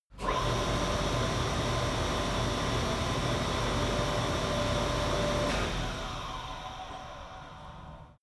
Gemafreie Sounds: Lüfter und Ventilatoren